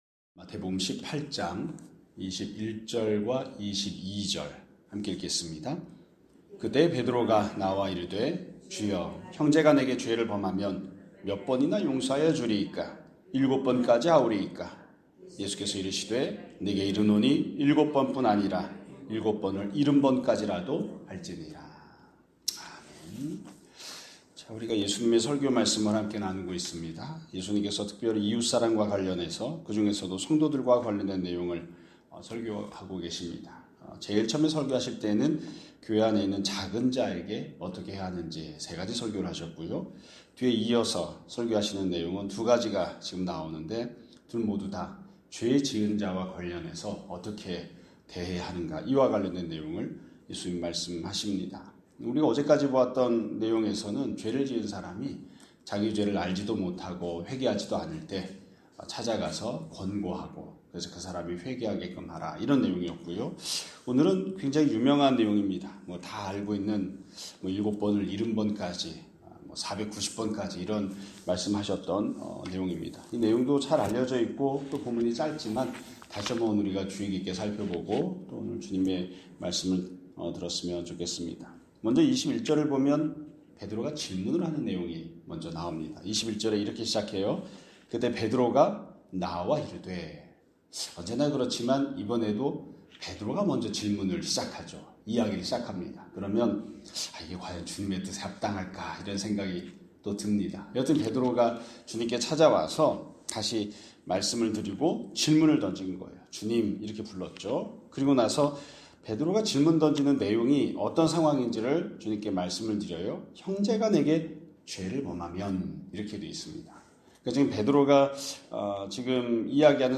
2025년 12월 12일 (금요일) <아침예배> 설교입니다.